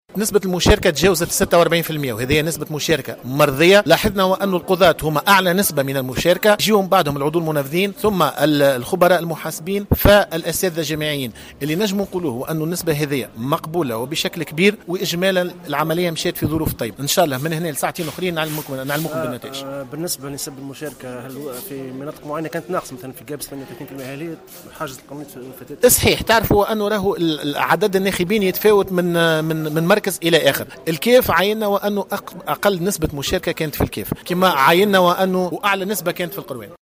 أكد عضو الهيئة العليا للانتخابات نبيل بفون في تصريح لمراسل الجوهرة "اف ام" أن نسبة المشاركة في انتخابات المجلس الأعلى للقضاء تجاوزت 46 بالمائة وهي نسبة مشاركة مرضية .